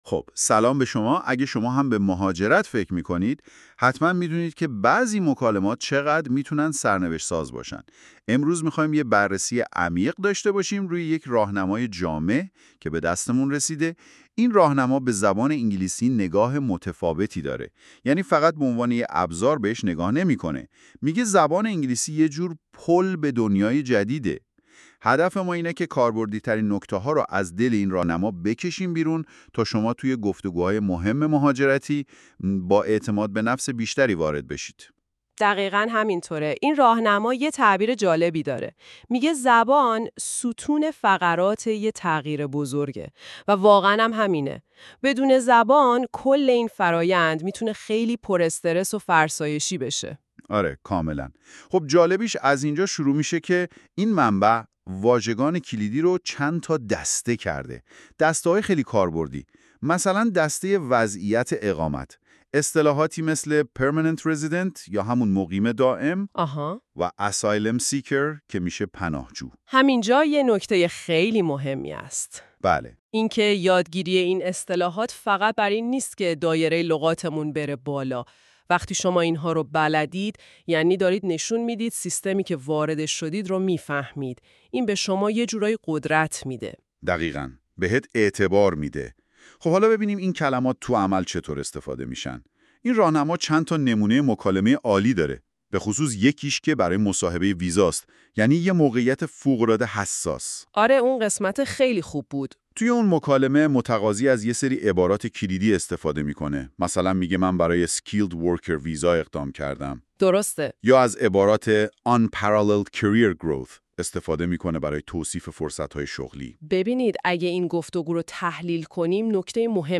english-conversation-immigration.mp3